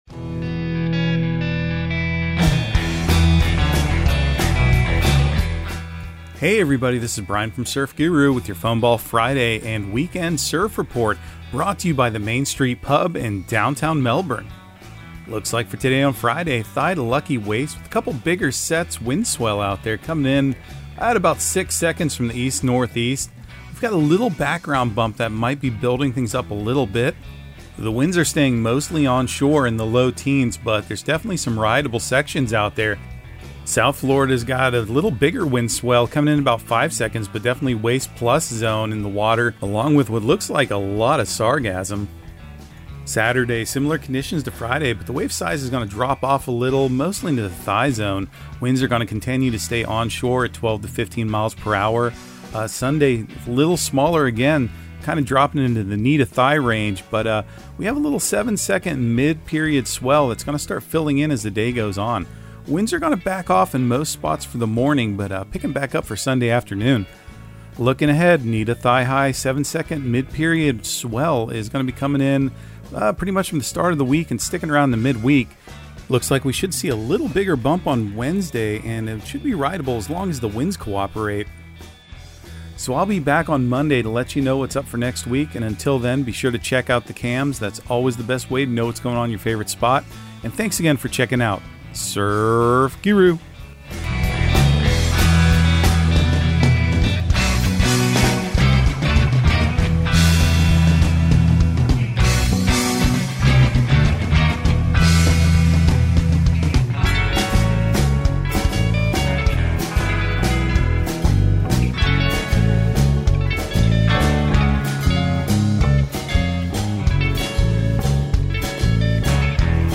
Surf Guru Surf Report and Forecast 05/12/2023 Audio surf report and surf forecast on May 12 for Central Florida and the Southeast.